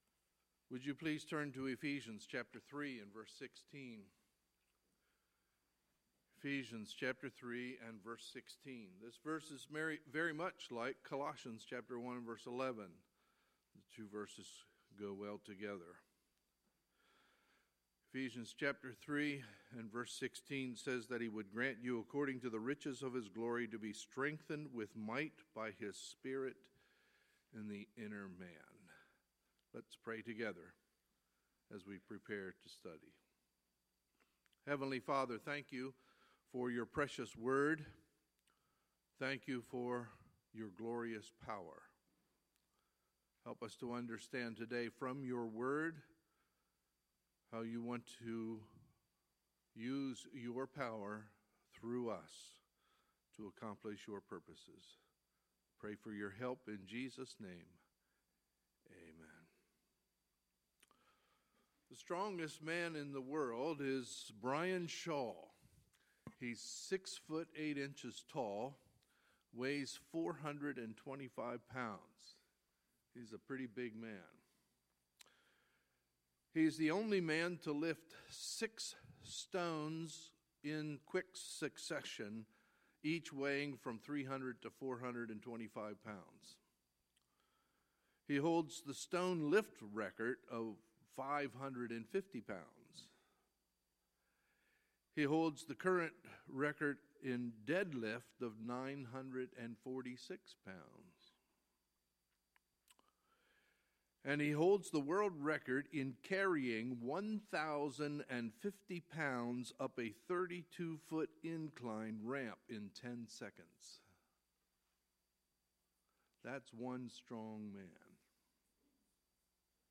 Sunday, January 15, 2017 – Sunday Morning Service
Sermons